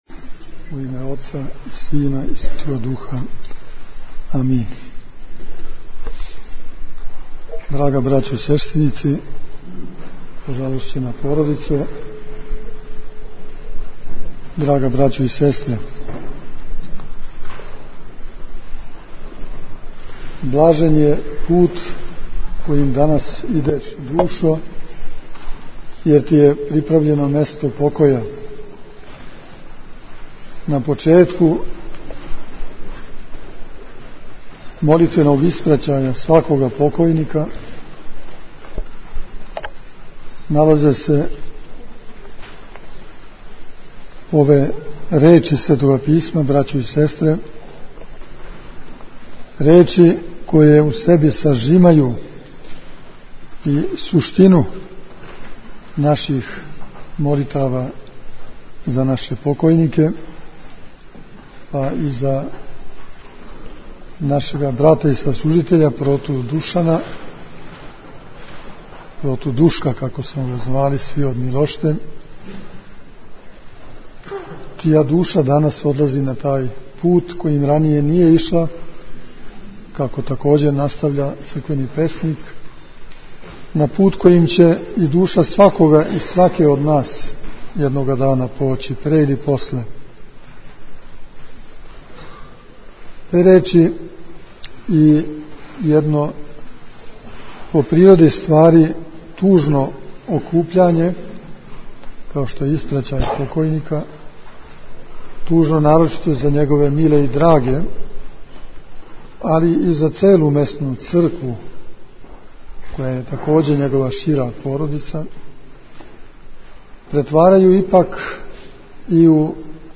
Беседа Епископа Иринеја